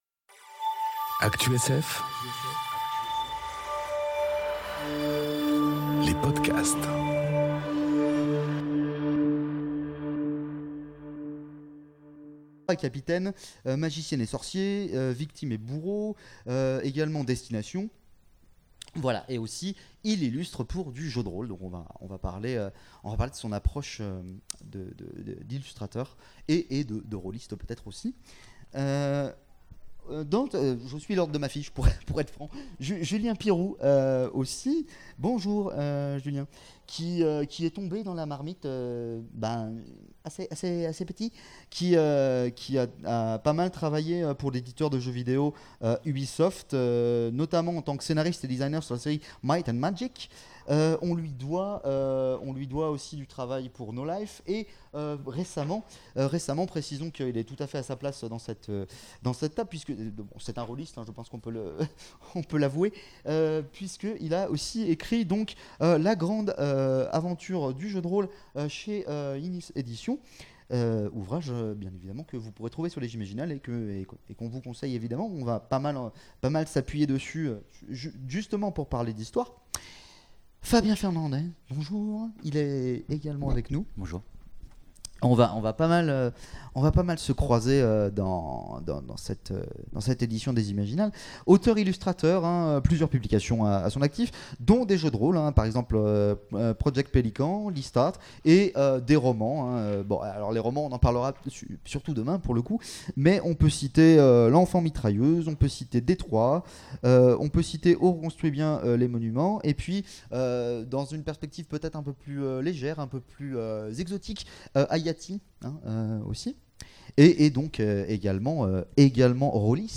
La conférence qui vous fera encore plus aimer le jeu de rôle
Imaginales-2021-Le-jeu-de-role-cet-incontournable-de-la-pop-culture.mp3